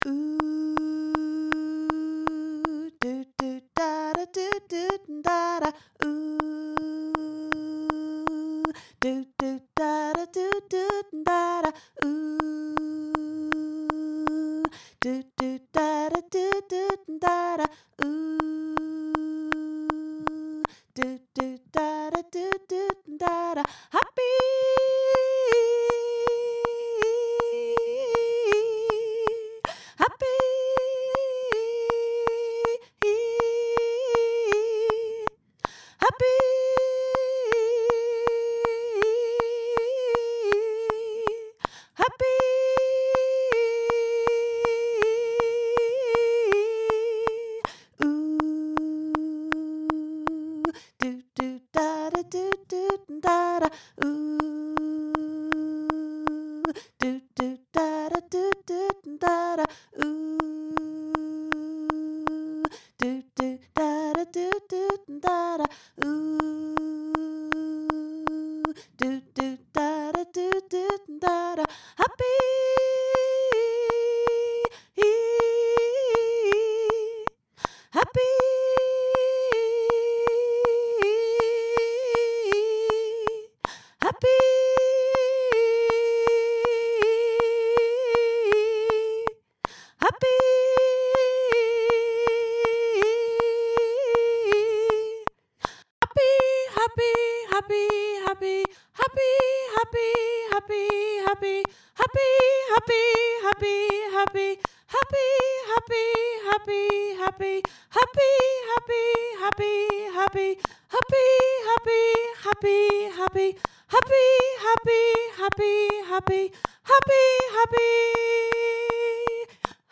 happy-breakaway-soprano.mp3